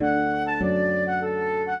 flute-harp
minuet4-10.wav